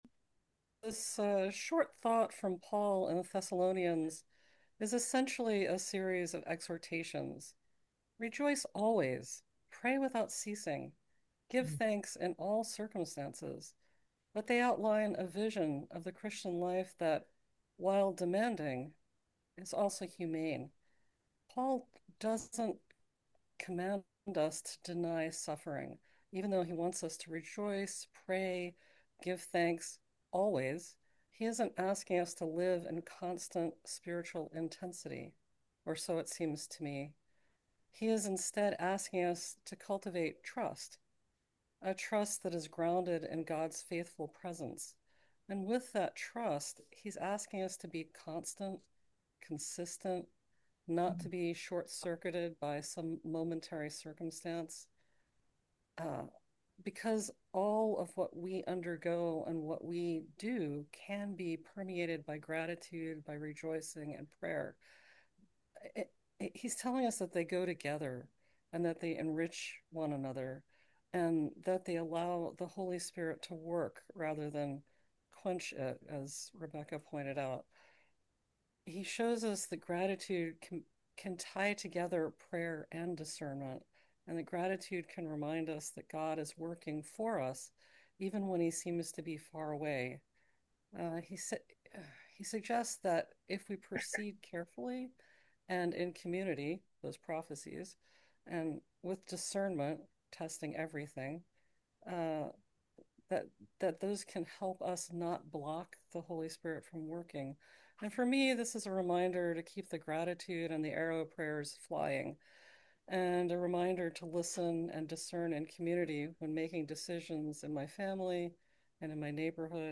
New Year’s Thanksgiving Prayer Service & Fellowship Zoom Event Reflections